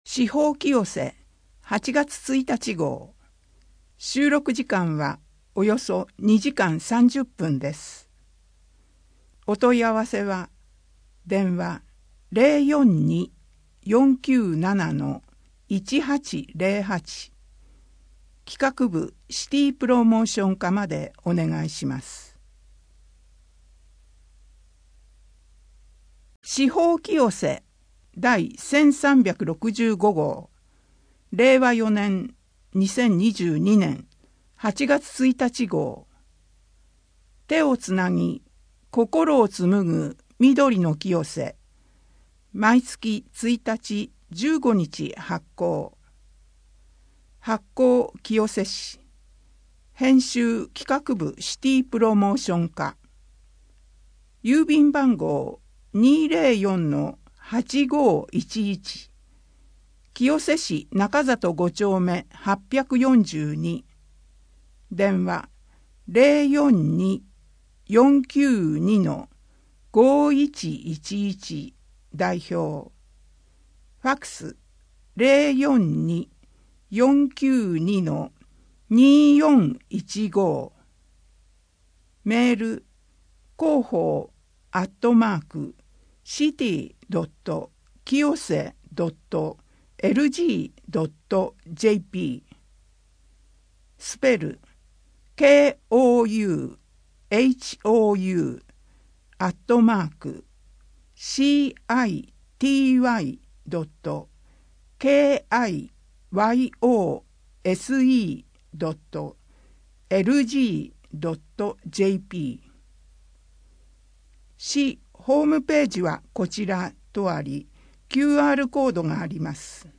核に関する資料の展示 8月の乳幼児健康診査 8・9月の子育て関連事業 8月の休日診療 24時間電話で聞ける医療機関案内 平日小児準夜間診療 令和4年8月1日号8面 （PDF 828.6KB） 声の広報 声の広報は清瀬市公共刊行物音訳機関が制作しています。